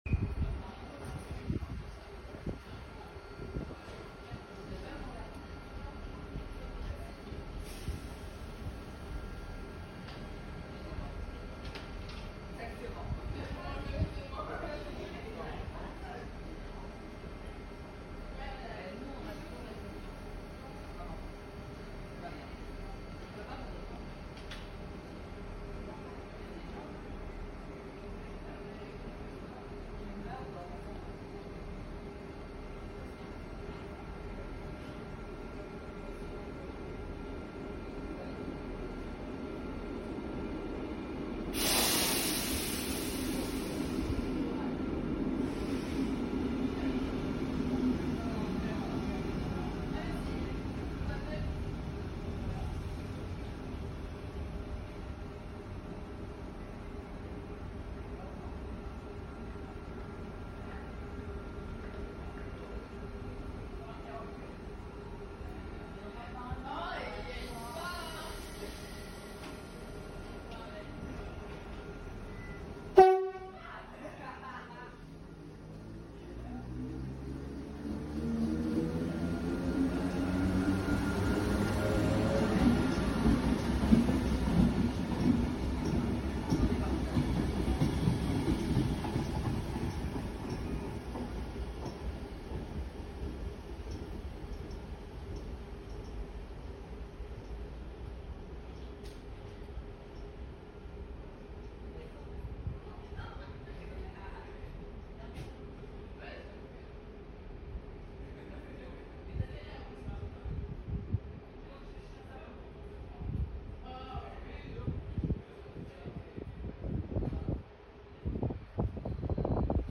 Spott d’une de l’AM classique sound effects free download
Spott d’une de l’AM classique num 660 +KLAXON